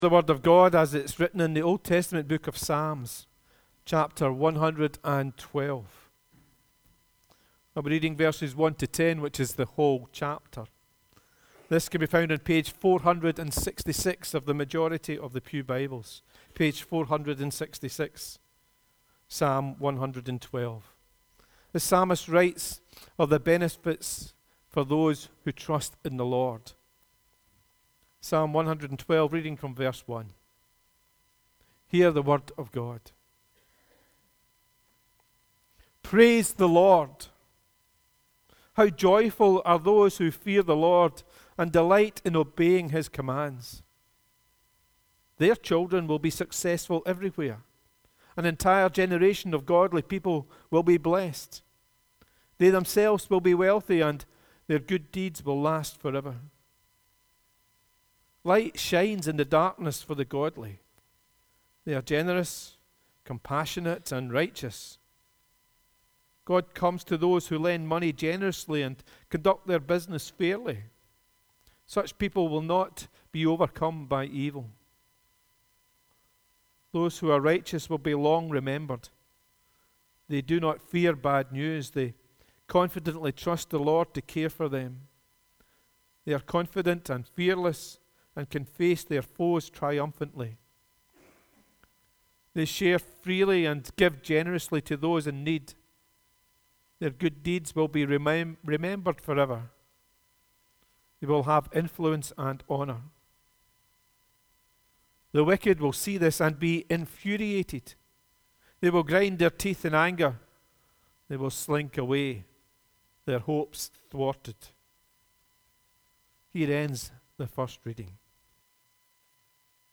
The readings prior to the sermon are Psalm 112: 1-10 and Luke 5: 17-26